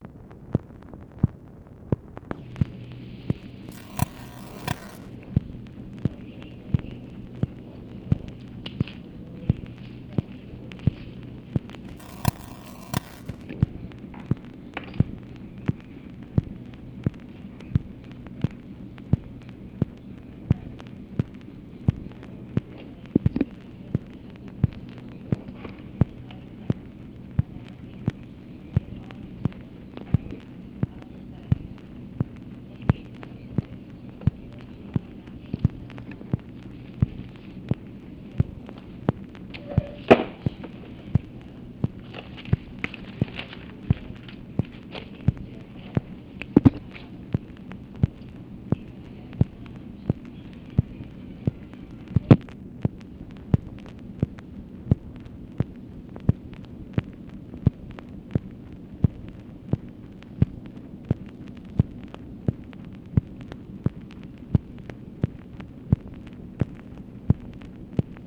OFFICE NOISE, August 3, 1966
Secret White House Tapes | Lyndon B. Johnson Presidency